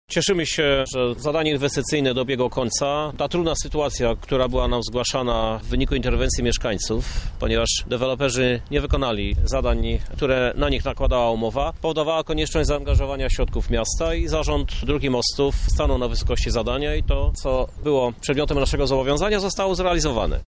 Miasto niekiedy ratunkowo musi realizować budowę dróg – mówi prezydent Lublina, Krzysztof Żuk.